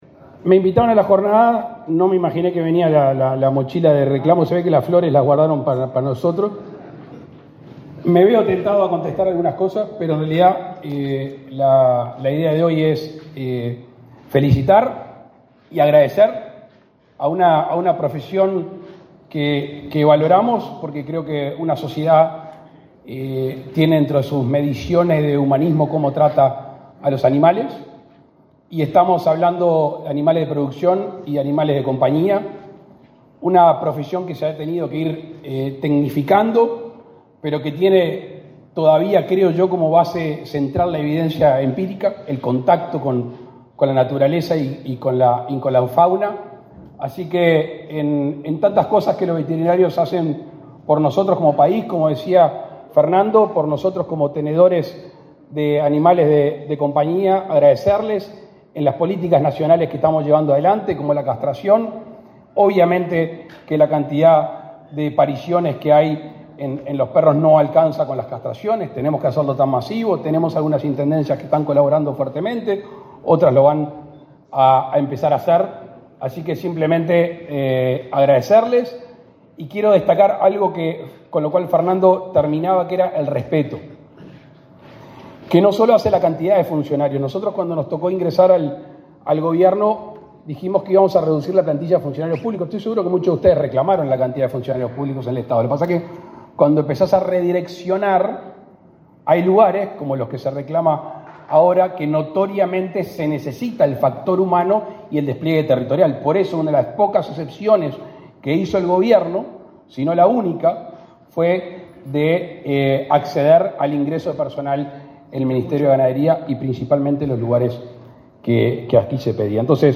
Palabras del presidente de la República, Luis Lacalle Pou
El presidente de la República, Luis Lacalle Pou, participó, este 8 de junio, en el acto inaugural de Jornadas Uruguayas de Buiatría.